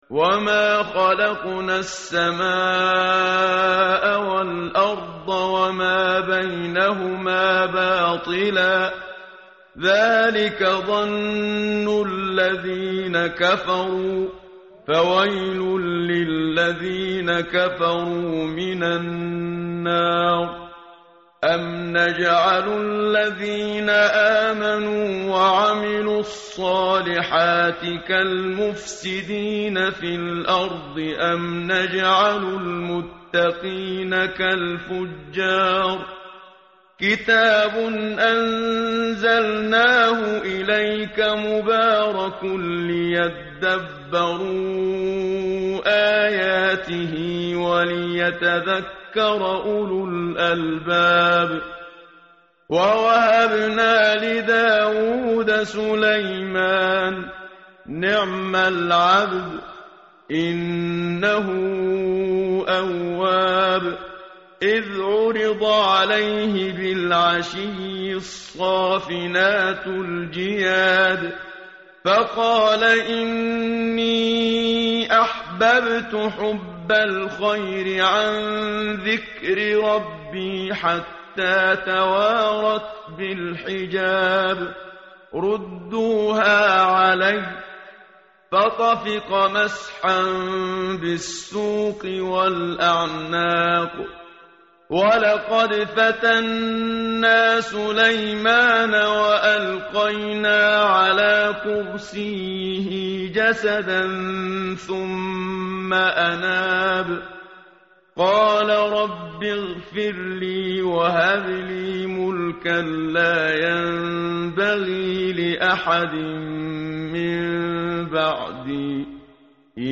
متن قرآن همراه باتلاوت قرآن و ترجمه
tartil_menshavi_page_455.mp3